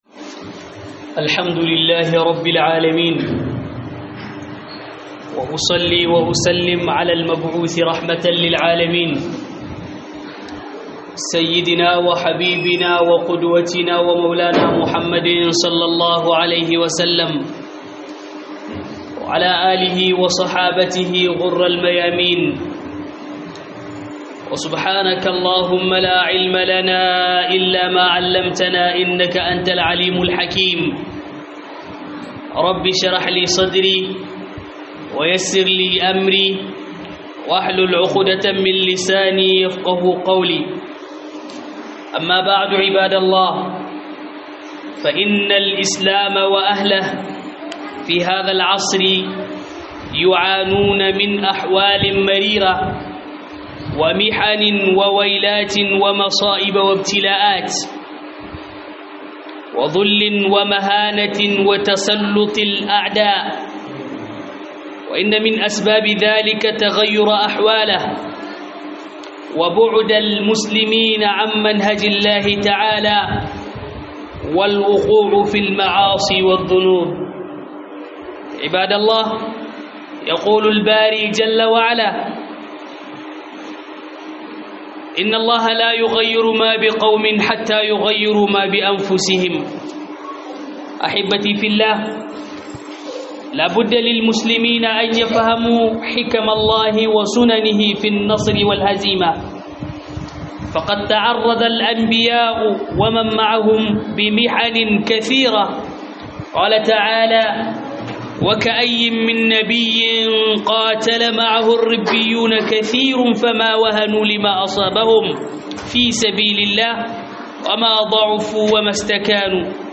Huɗubar juma'a Dalilan cin Nasara